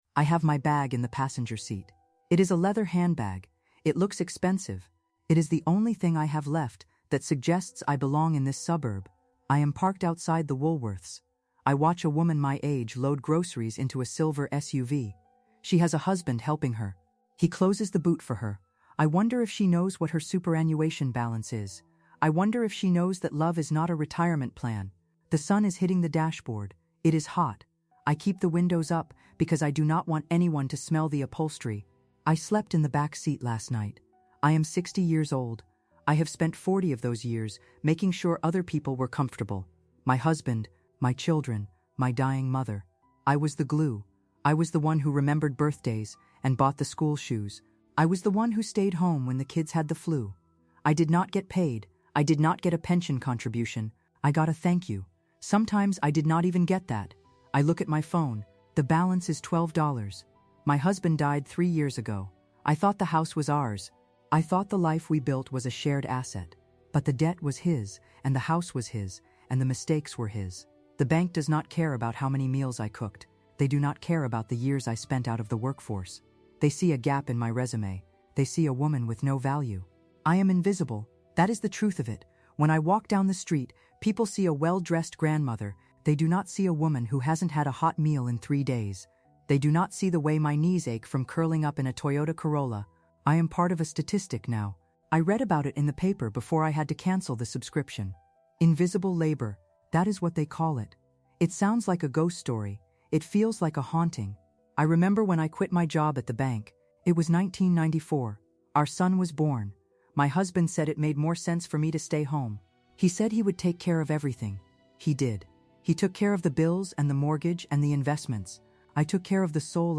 Based on the 2026 economic studies regarding unpaid caregiving and the motherhood penalty, "The Widow’s Web" tells the story of a woman who spent forty years building a home, only to find herself living out of her car. This first-person narrative dives deep into the systemic erasure of women’s financial security, revealing how a lifetime of domestic contribution can lead to zero retirement savings and sudden homelessness.
Through short, punchy, and emotionally grounded storytelling, we witness the transition from a comfortable suburban life to a desperate search for a bed in a shelter.